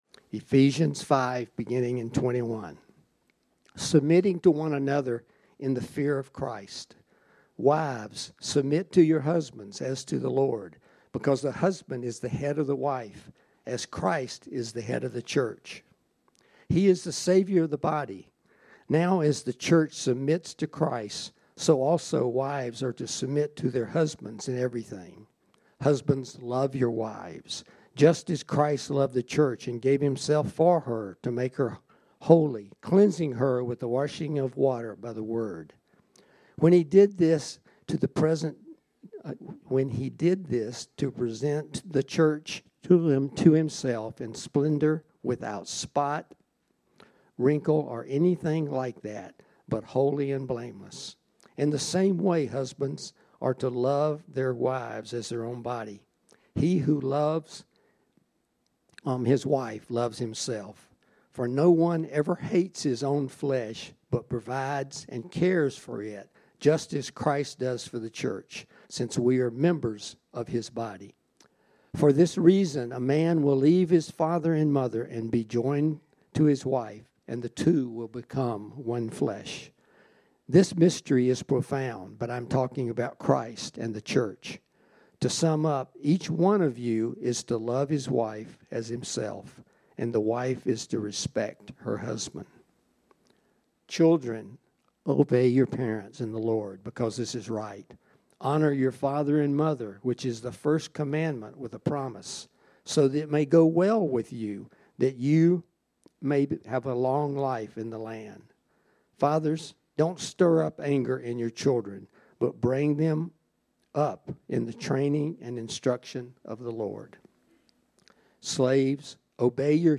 This sermon was originally preached on Sunday, November 19, 2023.